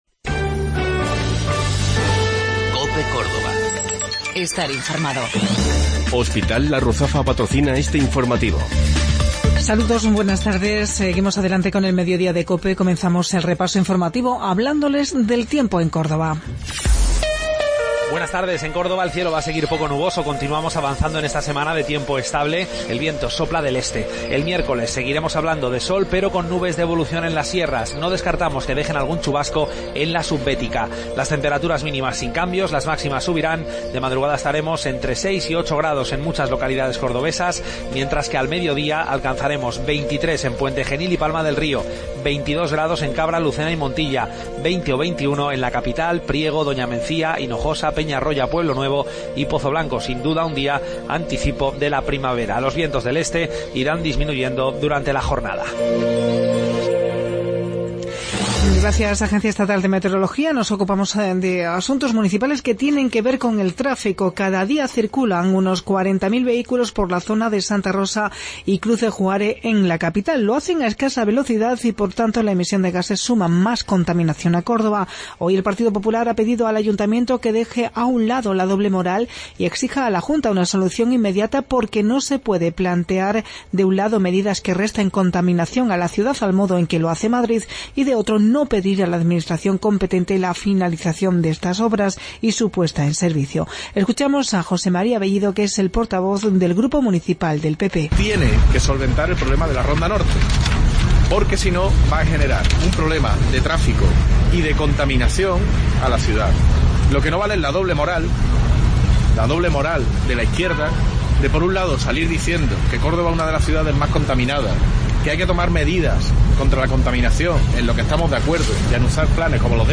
Mediodía en Cope. Informativo local 21 de Febrero 2017